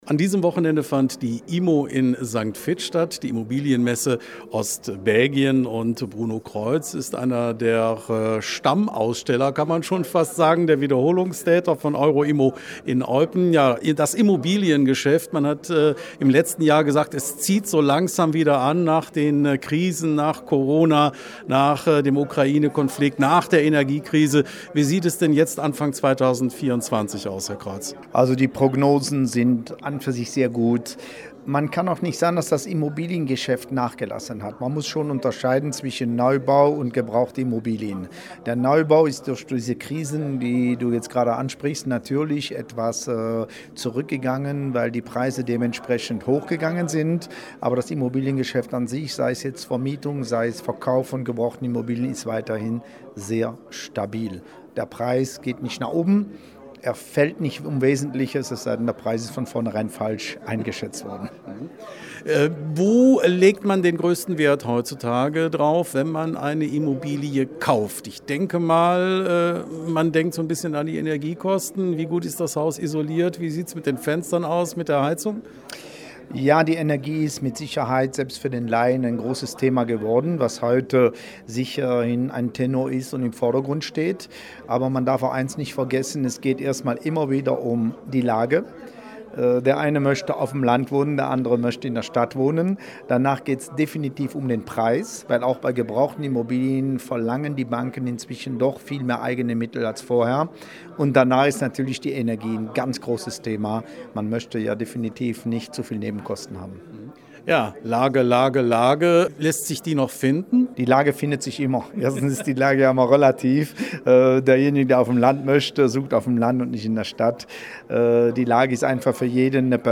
Am vergangenen Wochenende (13. & 14. Januar 2024) fand im Triangel St. Vith die IMO, die Immobilien-Messe Ostbelgien, statt.